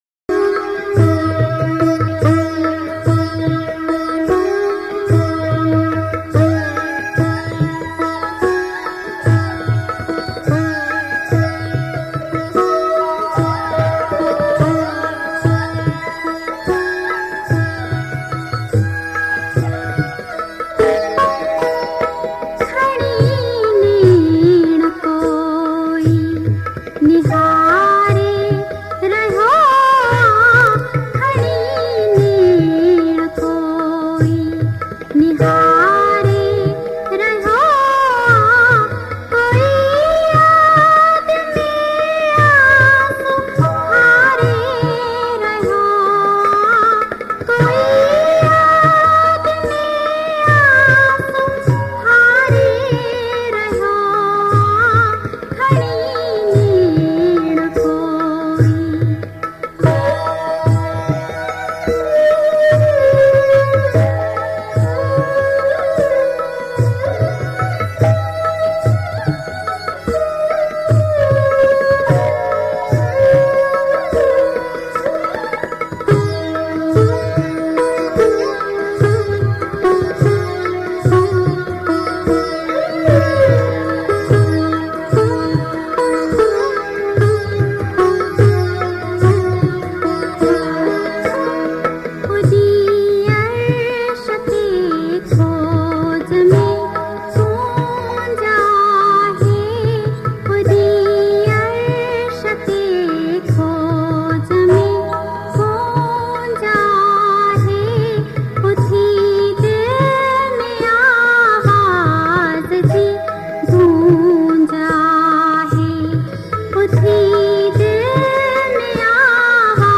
Sindhi Kalam, Geet, Qawali, Duet